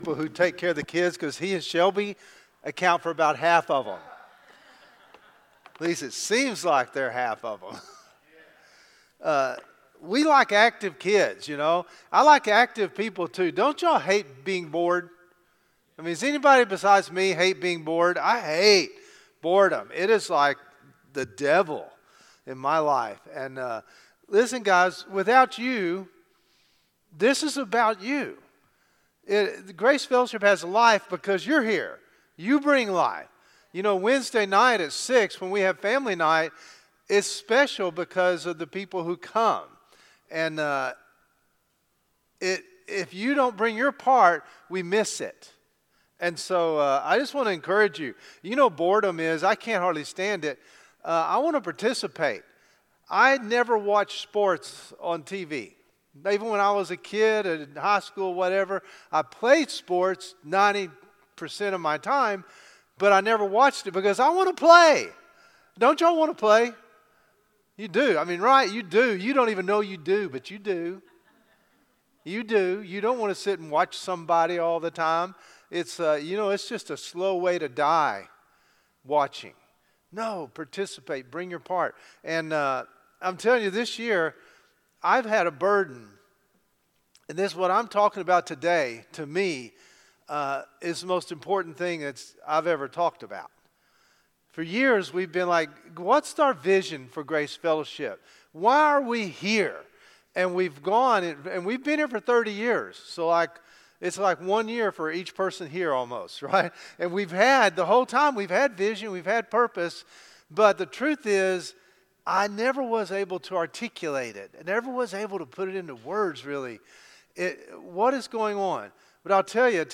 1 Morning Service